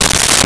Wood Crack 02
Wood Crack 02.wav